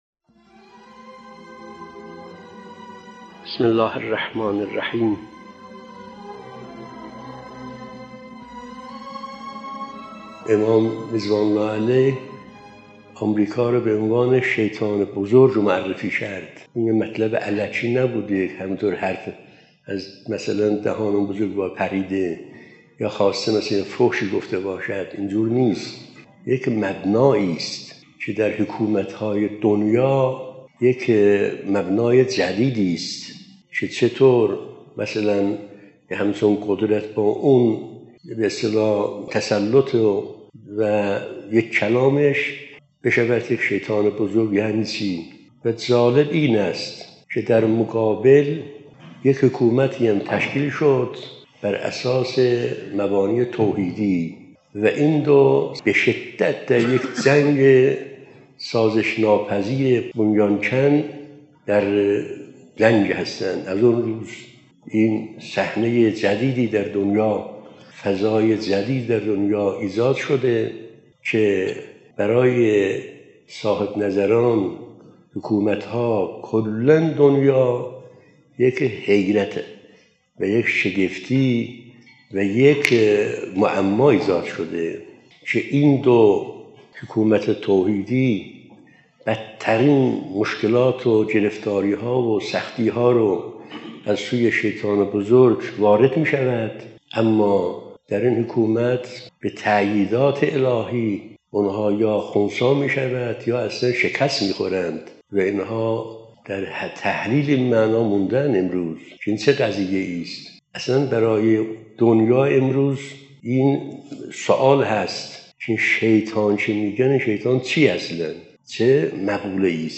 📍از جلسه اولین جمعه ماه قمری| ماه رجب 🎙انقلاب اسلامی (۸) 📌تشکیل حکومت اسلامی در مقابل شیطان بزرگ(آمریکا) ⏳۷ دقیقه 🔗پیوند دریافت👇 🌐